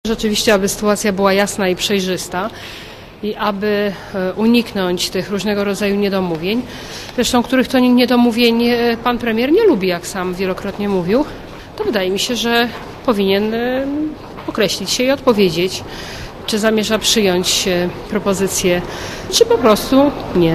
Ten apel jest zasadny - ocenia wiceszefowa SLD, Katarzyna Piekarska.
Komentarz audio